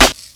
GUnit SD3.wav